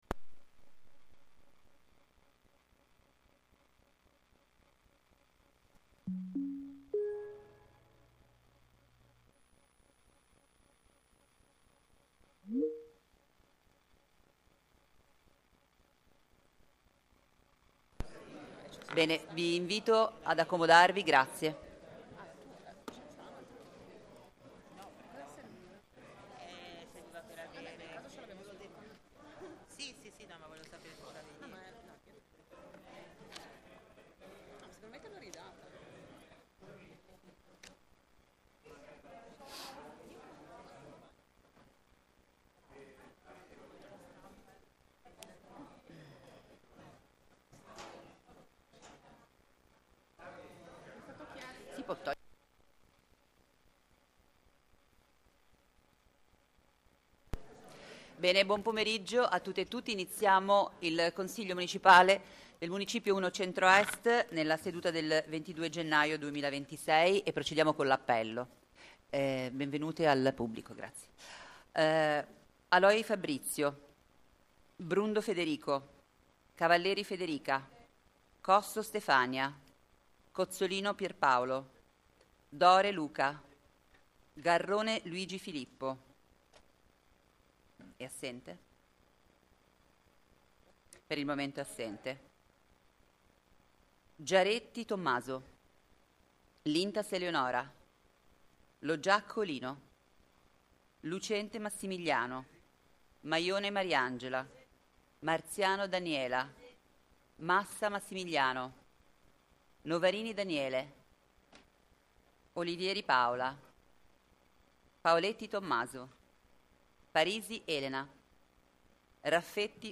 Seduta di Consiglio del Municipio I | Comune di Genova
Consiglio Municipio I CentroEst di giovedi 22 gennaio 2026 ore 1300.mp3